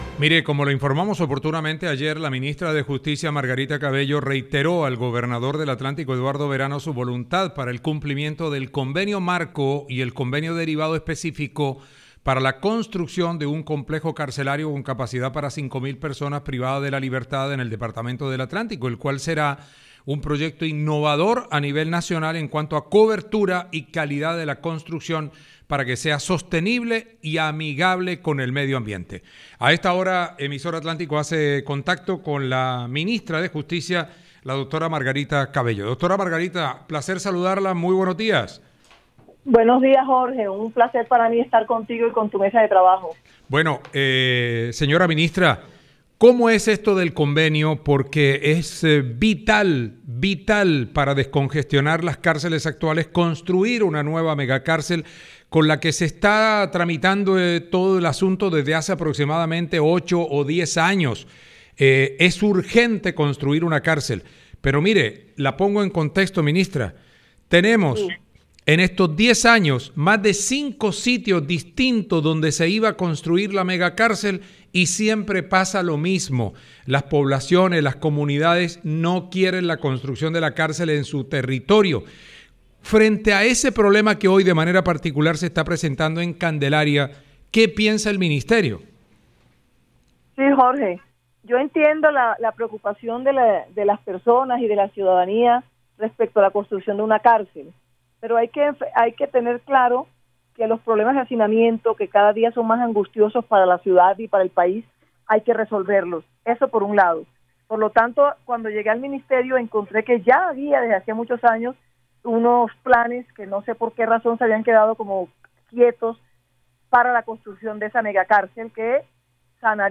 La ministra de Justicia, Margarita Cabello, en diálogo con Emisora Atlántico, defendió el proyecto de construcción de la megacárcel en jurisdicción del municipio de Candelaria.